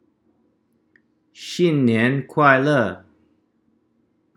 Mandarin: xīn nián kuài lè
xin-nian-kuai-le.mp3